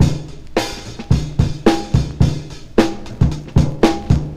• 110 Bpm Breakbeat Sample F Key.wav
Free drum beat - kick tuned to the F note. Loudest frequency: 582Hz
110-bpm-breakbeat-sample-f-key-D9N.wav